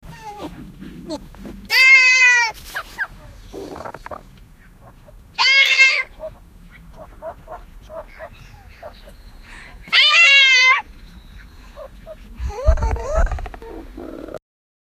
Child Scream, Pig Noises, Frog Noises, Irish Dancing, Hooves
child-scream-pig-noises-f-lpxnl7wq.wav